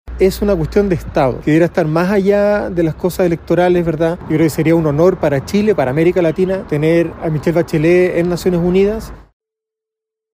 Aunque algunas voces del Gobierno ya han señalado que la inscripción de la expresidenta se realizará posterior a la segunda vuelta para establecer conversaciones con quien llegue a La Moneda, desde el oficialismo, el diputado electo del Frente Amplio, Jaime Bassa, sostuvo que la candidatura tiene fines de Estado y que no se debe mezclar con los comicios.